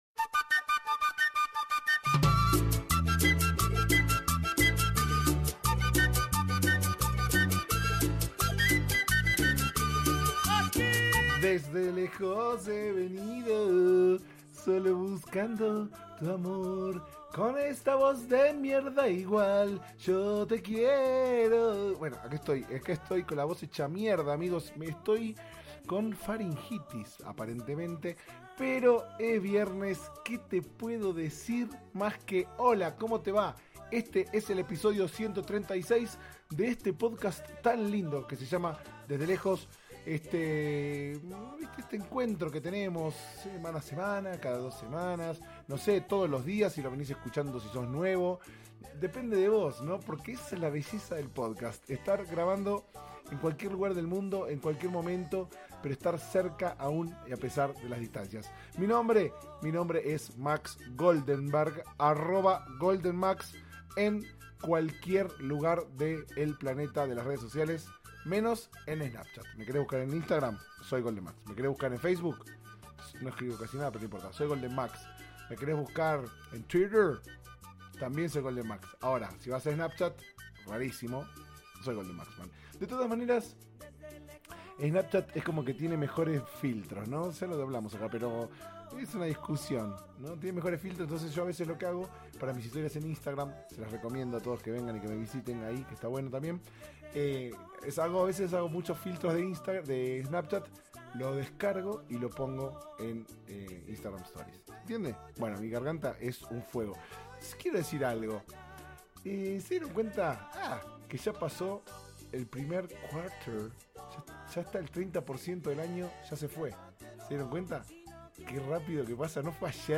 Ni una maldita faringitis que me tiene a maltraer hace algunos días.
Pasen, oigan esta voz arenosa y tanguera (?) contar peripecias varias.